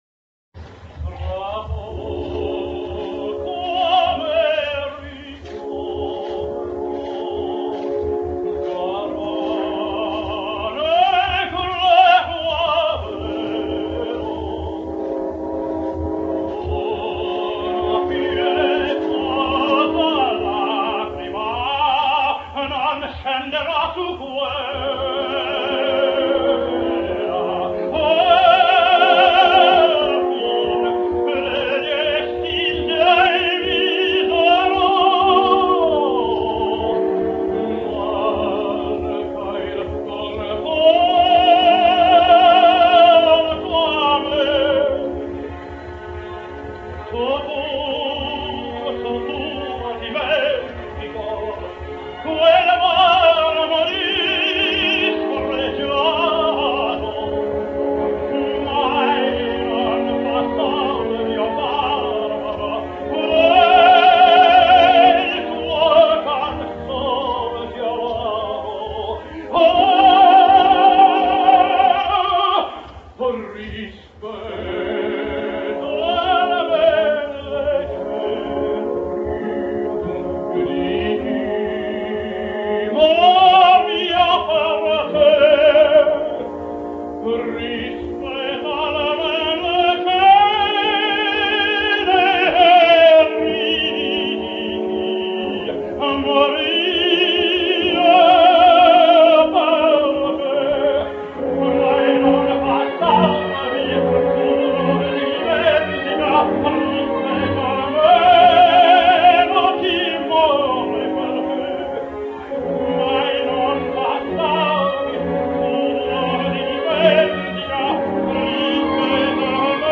James Melton sings Lucia di Lammermoor: